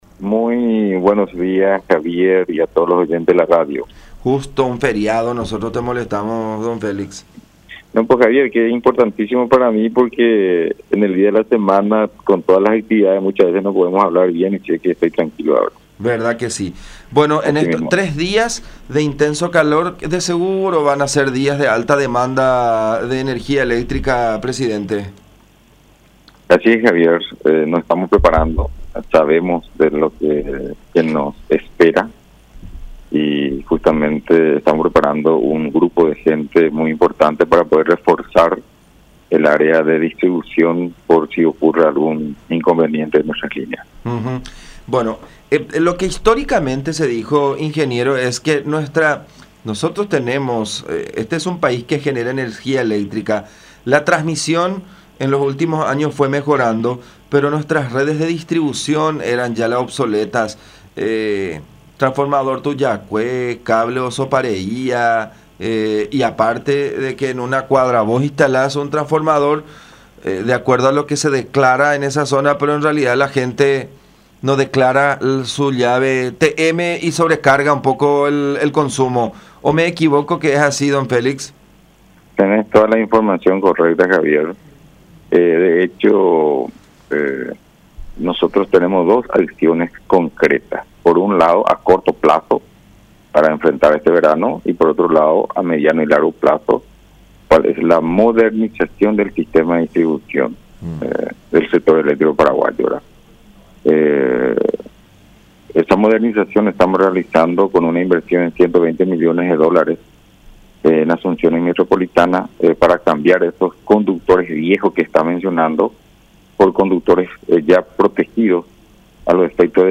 El ing. Félix Sosa, presidente de la Administración Nacional de Electricidad (ANDE) detalló los 4 puntos específicos sobre los cuales trabaja la institución para dar respuesta a la alta demanda del consumo de energía eléctrica en el verano.